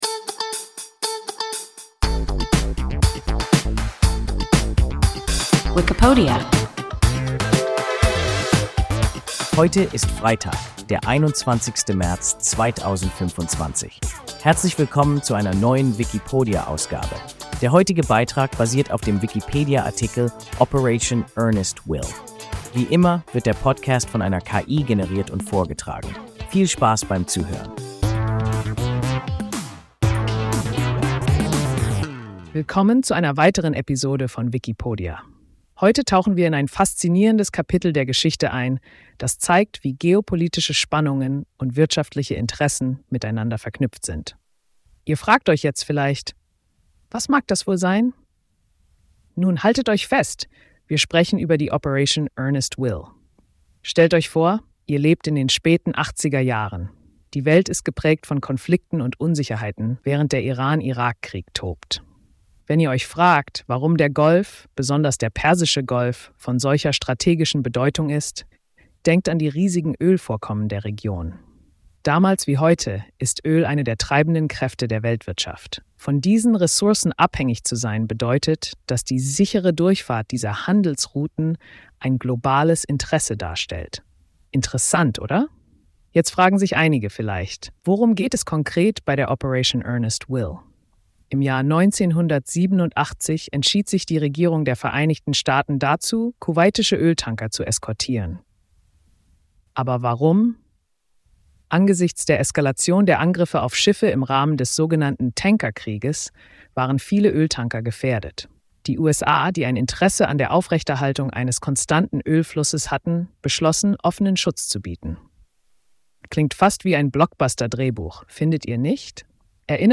Operation Earnest Will – WIKIPODIA – ein KI Podcast